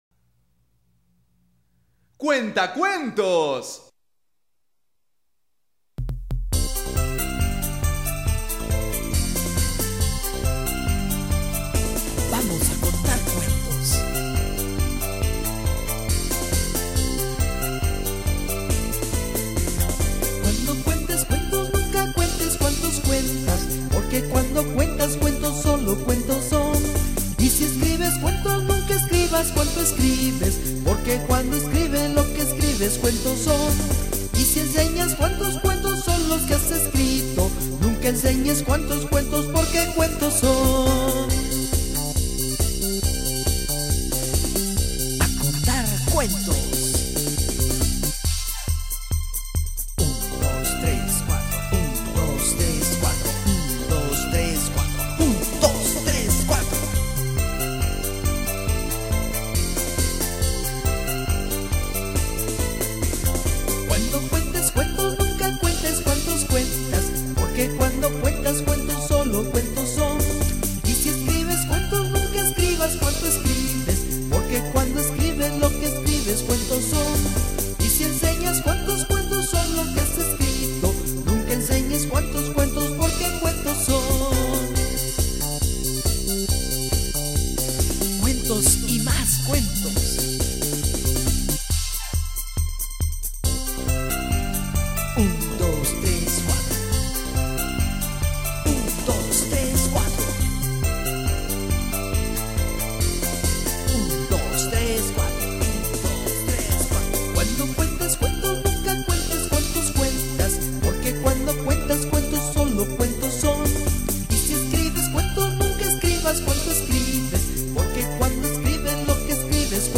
Rondas y Canciones Infantiles Para niños
Rondas Infantiles